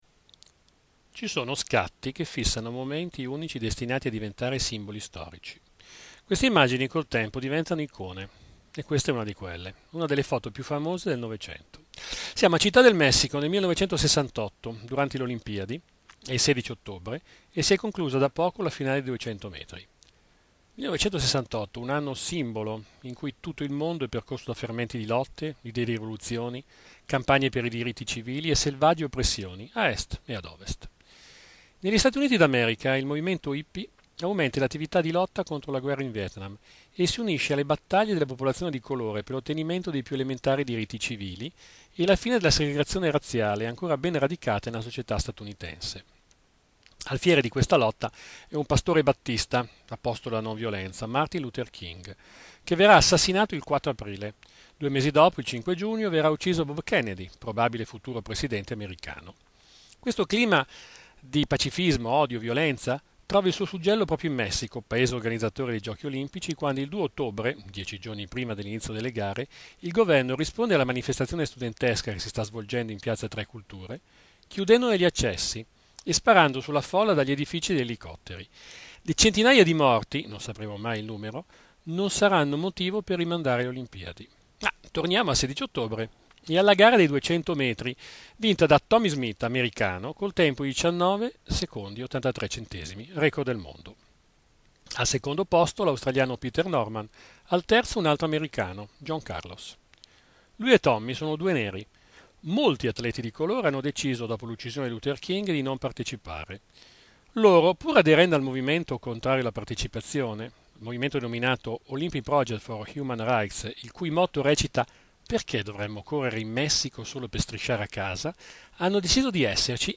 Puntate della rubrica radiofonica l'Angolo di Orso Curioso trasmessa da Web Pieve Radio